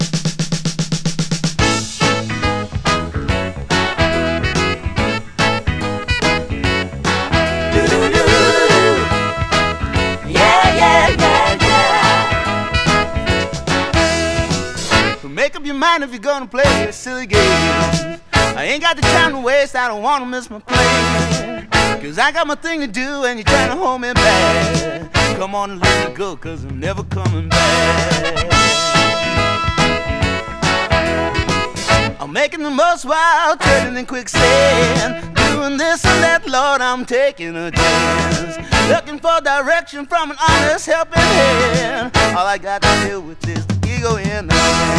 ポップ〜AOR風味漂う爽やかなハワイアン・アコースティック・デュオ
ふたりのボーカル、ハーモニーも魅力的。
Rock, Pop, Hawaii　USA　12inchレコード　33rpm　Stereo